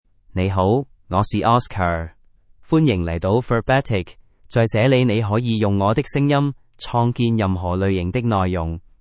Oscar — Male Chinese (Cantonese, Hong Kong) AI Voice | TTS, Voice Cloning & Video | Verbatik AI
MaleChinese (Cantonese, Hong Kong)
Oscar is a male AI voice for Chinese (Cantonese, Hong Kong).
Voice sample
Listen to Oscar's male Chinese voice.
Oscar delivers clear pronunciation with authentic Cantonese, Hong Kong Chinese intonation, making your content sound professionally produced.